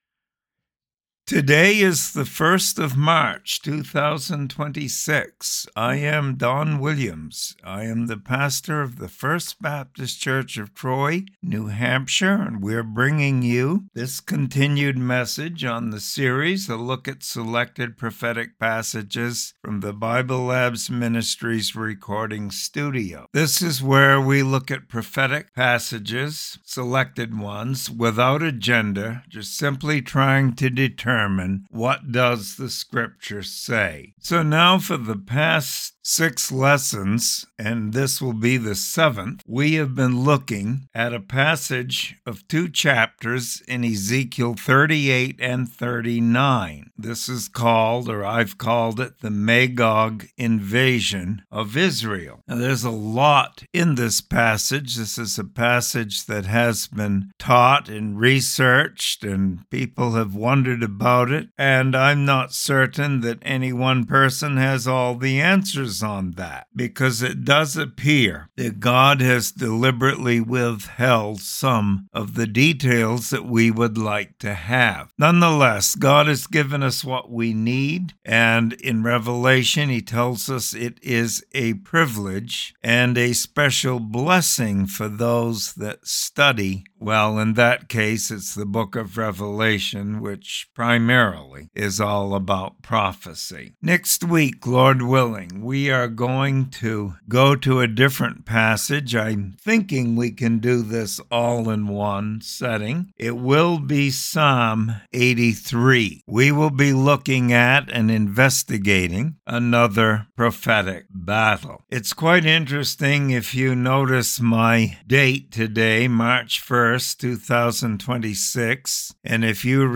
Bible Study and Commentary on Ezekiel 39:17-29.